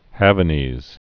(hăvə-nēz)